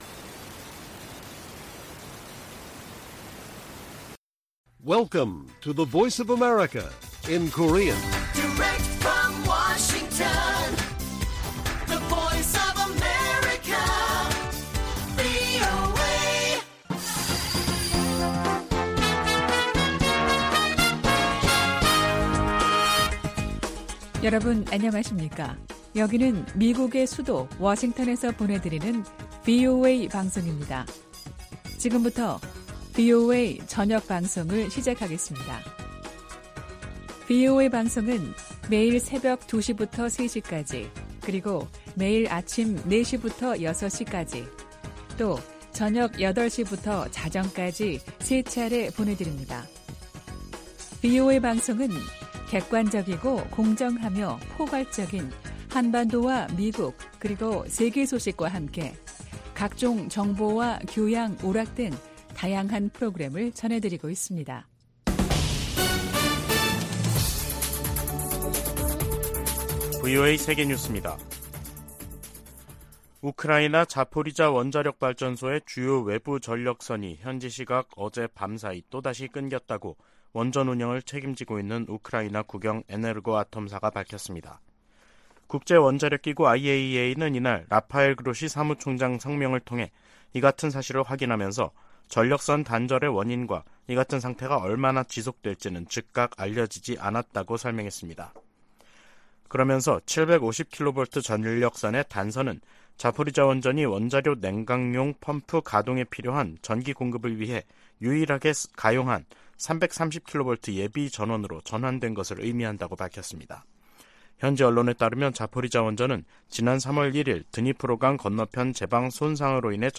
VOA 한국어 간판 뉴스 프로그램 '뉴스 투데이', 2023년 7월 5일 1부 방송입니다.